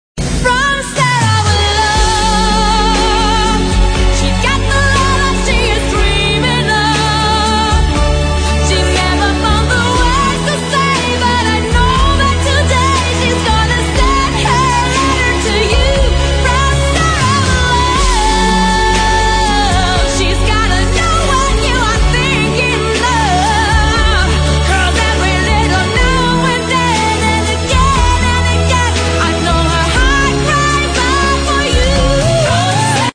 分类: MP3铃声
funky music